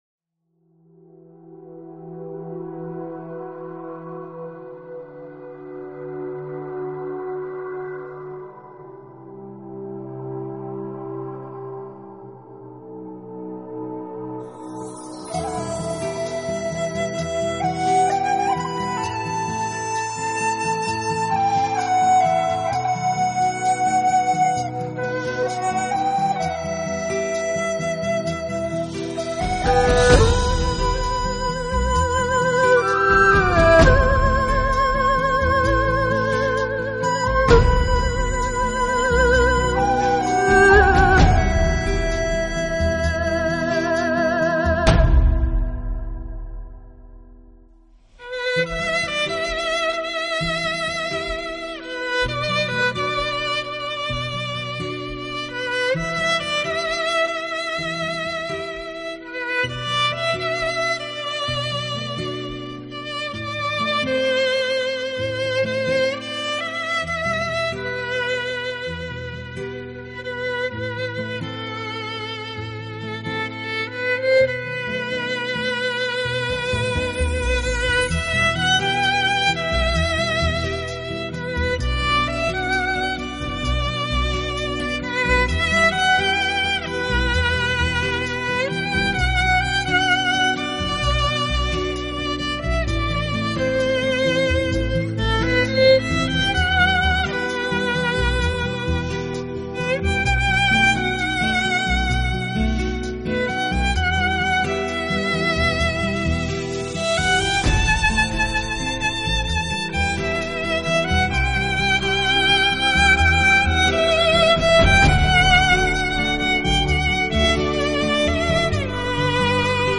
专辑类型：国语流行
水，韵味深长的演奏，配以多情的二胡，古筝，竹笛，巴乌等彰显中国情怀的民族
柔美真切，细腻动人。
其做工极为细致，外观古典大方，共鸣饱满，弦体明亮。